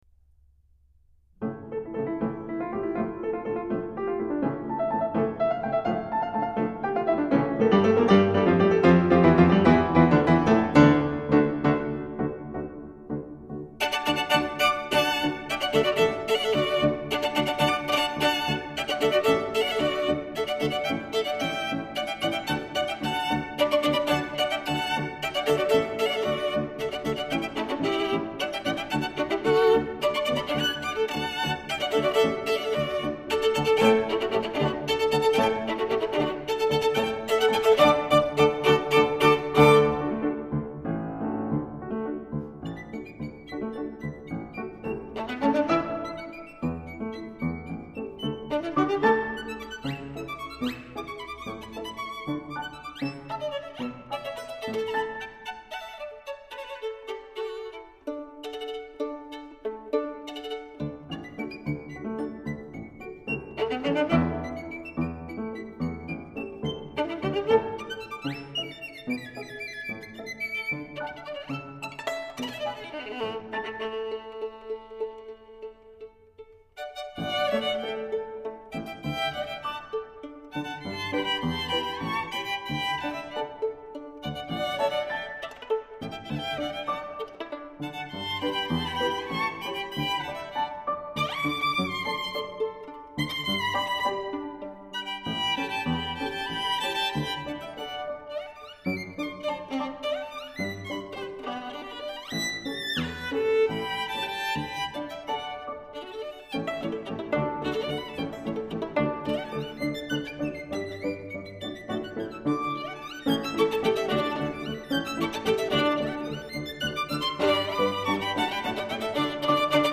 阿玛悌小提琴
史坦威钢琴
可以拿来测试百万音响的小提琴名盘
的一生当中，以西班牙传统舞曲为主题，创作了不少旋律优美、脍炙人口的小提琴曲子。